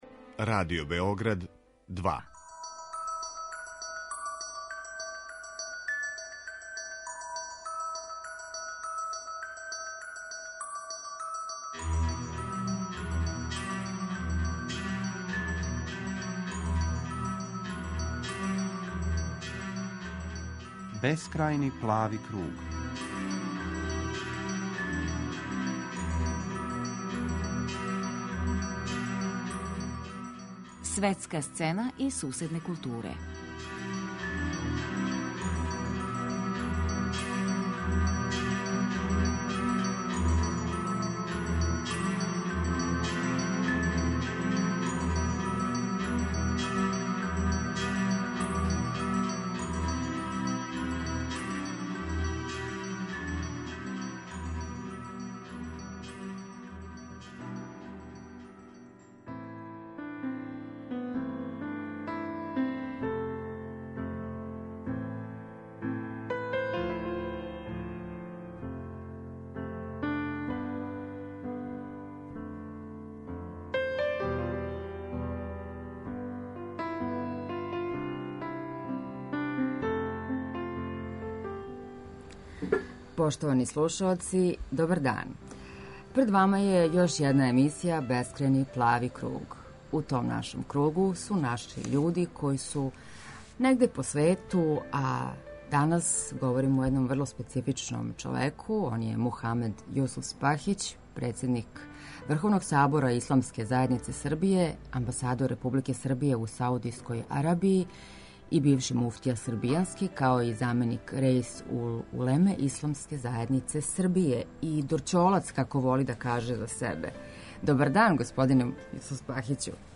Гост у емисији Бескрајни плави круг биће Мухамед Јусуфспахић, амбасадор Републике Србије у Саудијској Арабији.